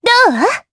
Erze-Vox_Attack3_jp.wav